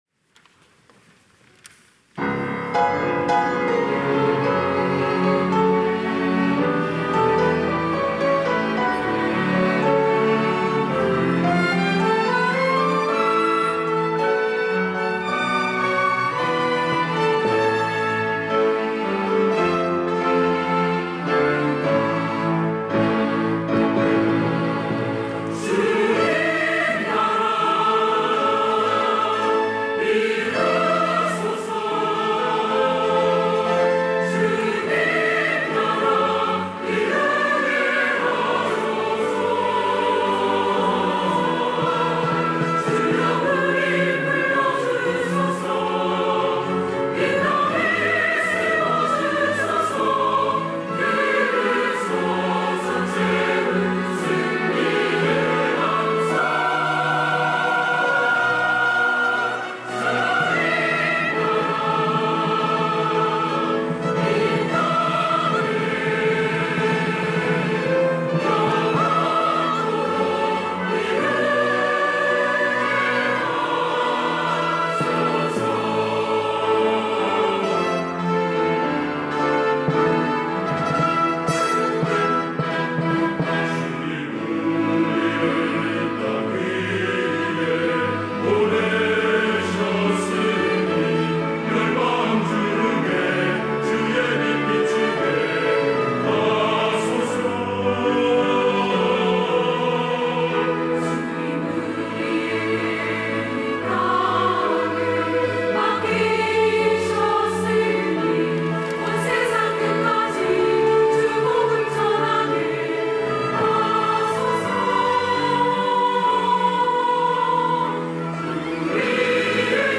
특송과 특주 - 주님 나라 이루게 하소서
천안중앙교회 찬양대